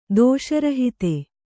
♪ dōṣa rahite